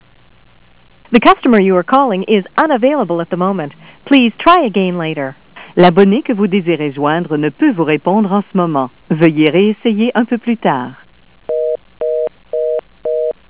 AOpen FM56-P (H) Speakerphone PCI modem